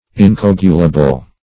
Incoagulable \In`co*ag"u*la*ble\, a.